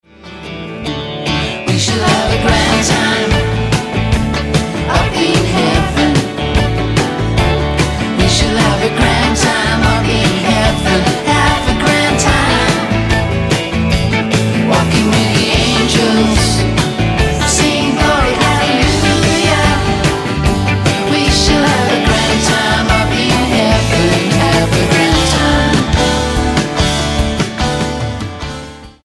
STYLE: Rock
wispy vocals are bolstered by layered background vocals